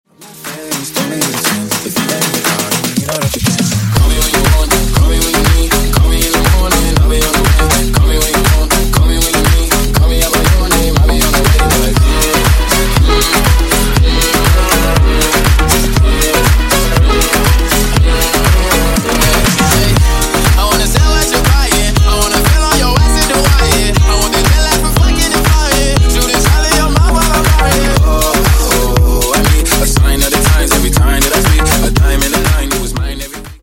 Клубные Рингтоны » # Громкие Рингтоны С Басами
Рингтоны Ремиксы
Танцевальные Рингтоны